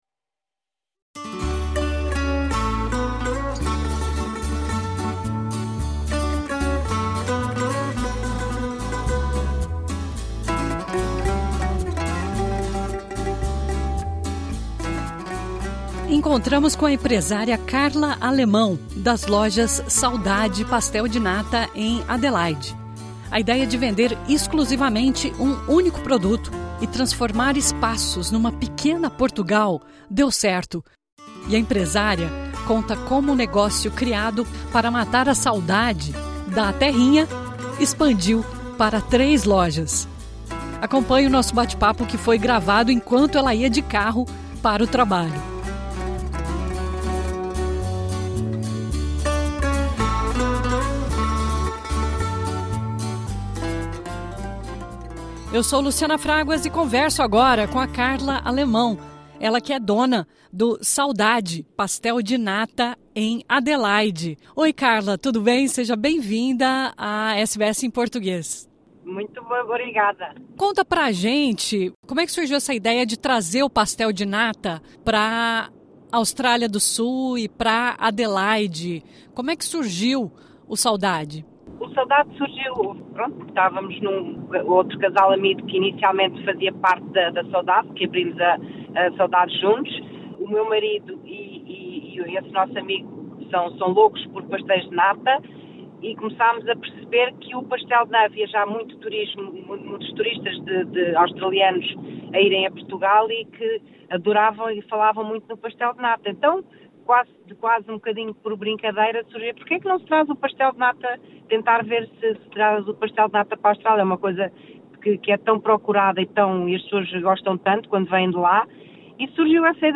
Acompanhe o nosso bate papo que foi todo gravado enquanto ela ia de carro para uma das lojas.